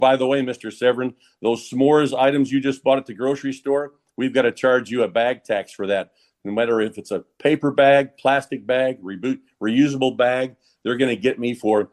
Severin spoke during a virtual press conference on Wednesday.